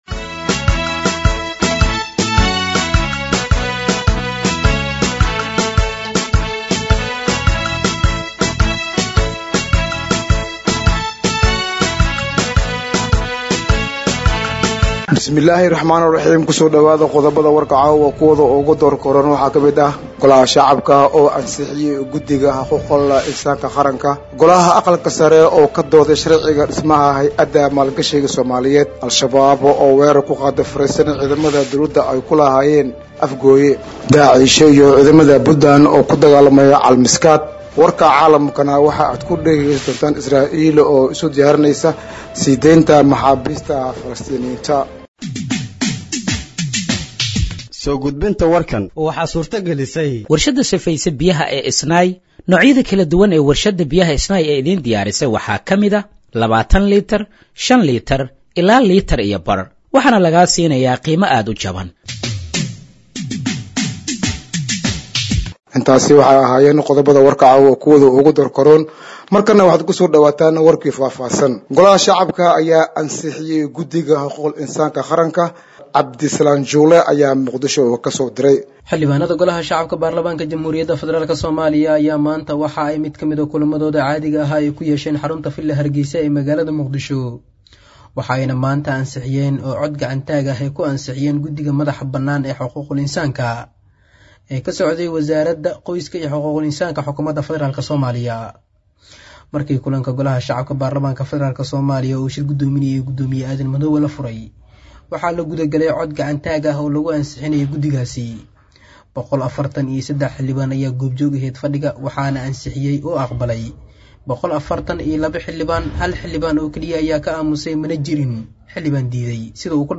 Dhageeyso Warka Habeenimo ee Radiojowhar 11/10/2025
Halkaan Hoose ka Dhageeyso Warka Habeenimo ee Radiojowhar